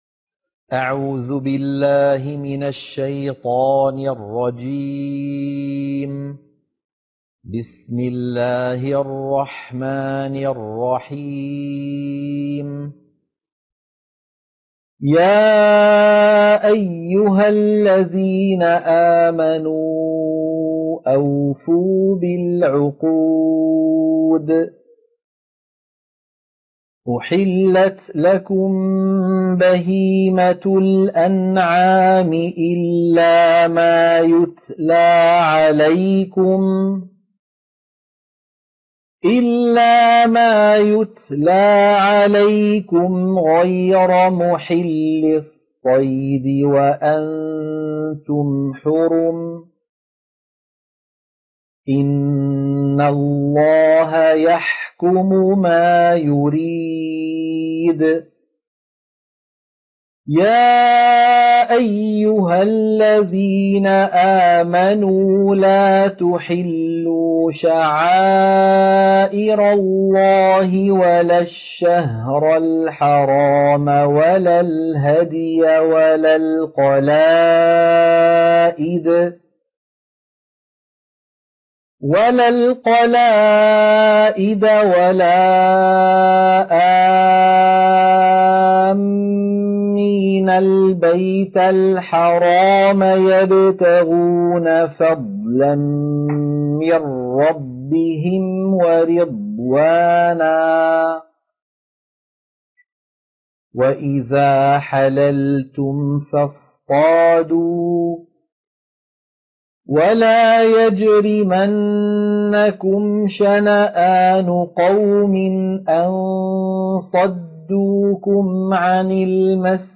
سورة المائدة - القراءة المنهجية